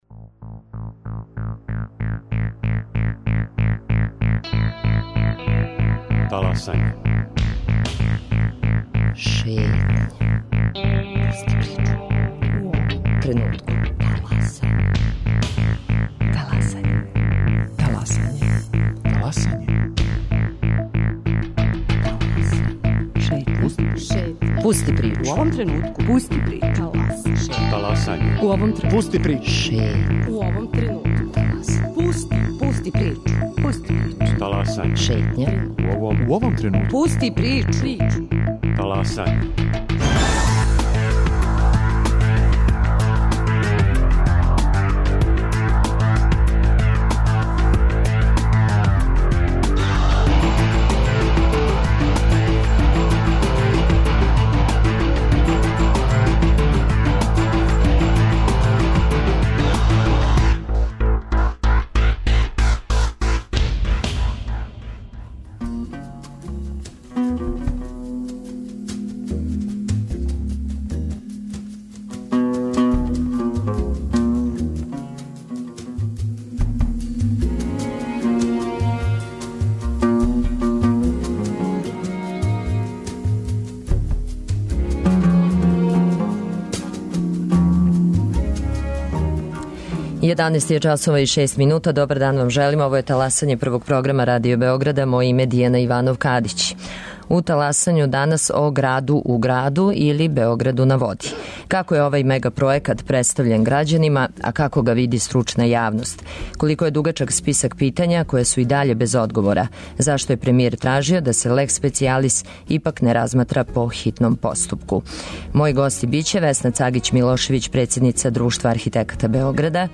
Tema emisije "Talasanje" na Radio Beogradu bio je Beograd na vodi.